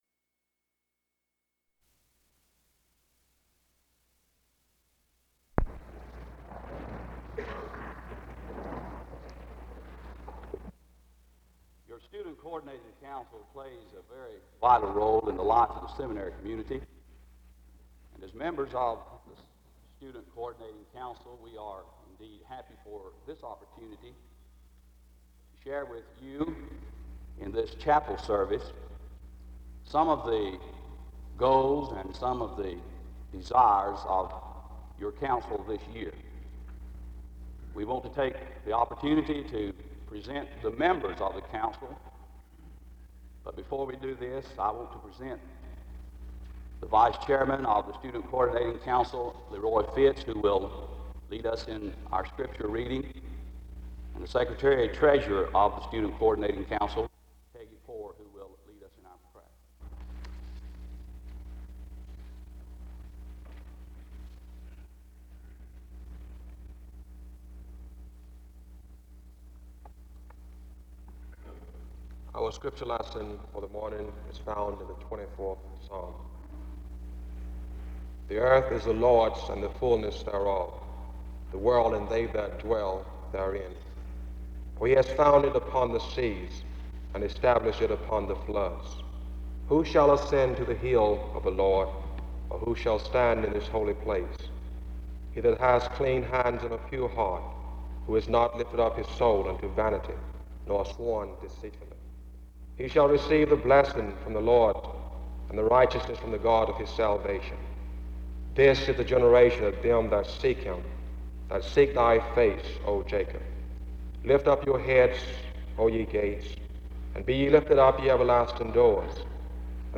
SEBTS Chapel - Student Coordinating Council September 25, 1969